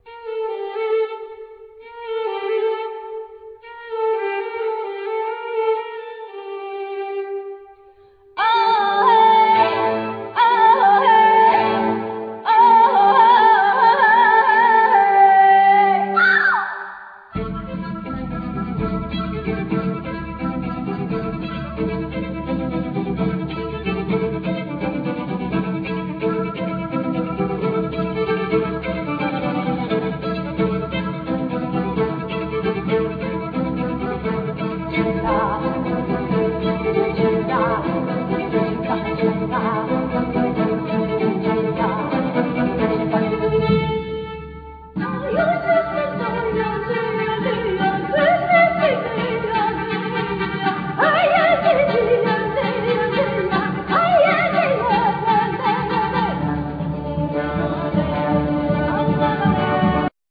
Vocals,Violin
Winds,Bass,Percussions,etc